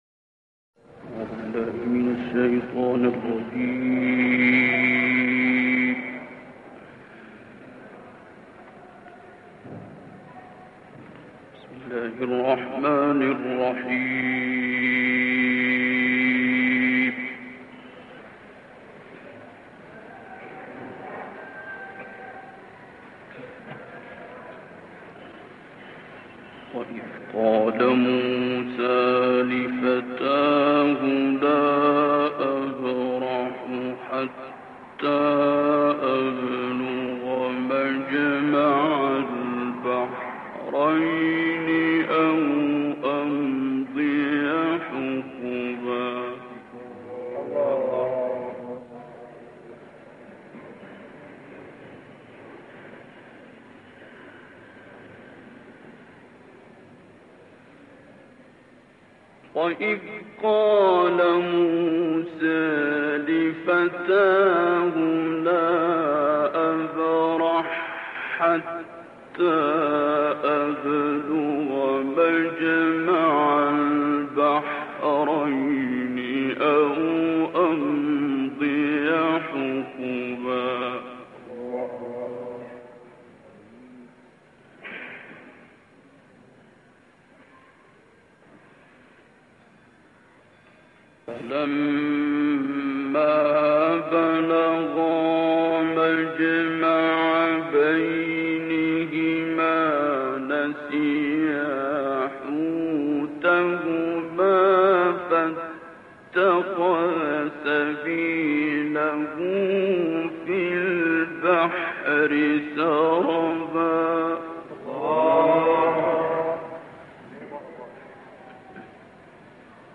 سوره کهف با صدای استاد عبدالباسط + دانلود/ بیان سرنوشت شوم مستکبران